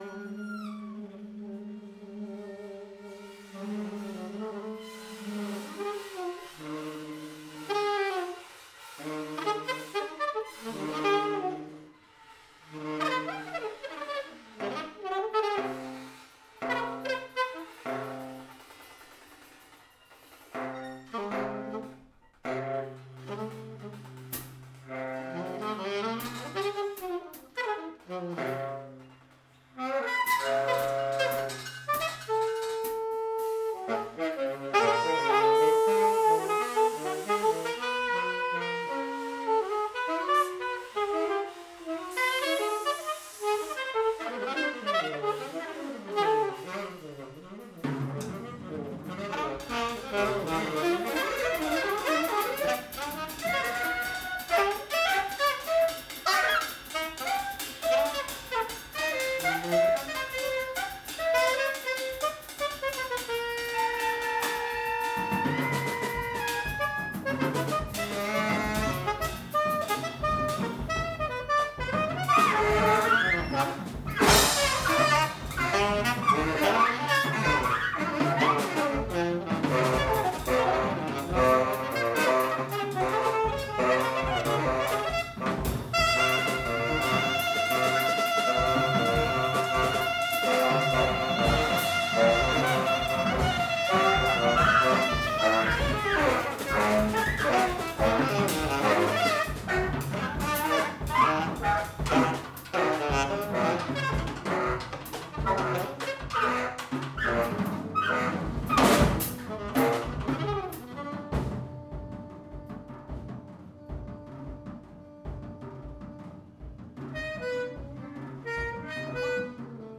alt sax
tenor sax
drums, percussions